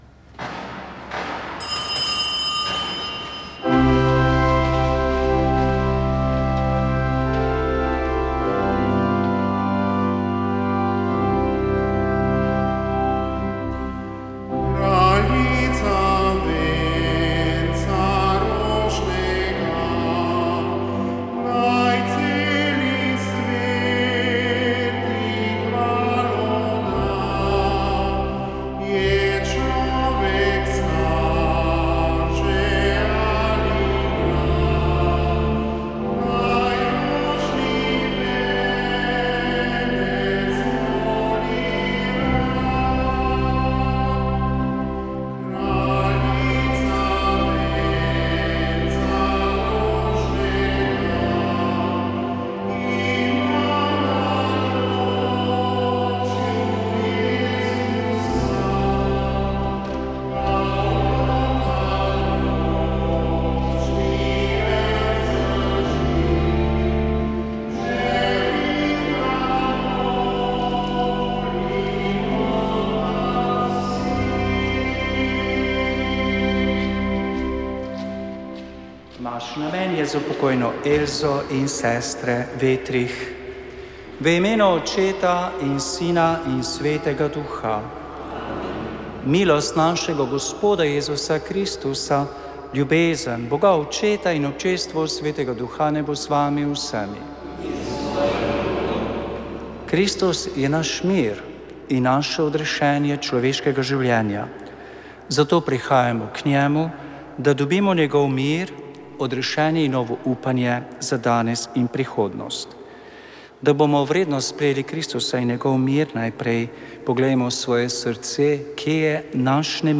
Sveta maša
Sv. maša iz cerkve Marijinega oznanjenja na Tromostovju v Ljubljani 31. 10.